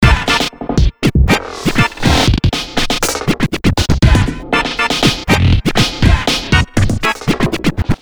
[影视音效][欢乐自high的音效][剪辑素材][音频素材下载]-8M资料网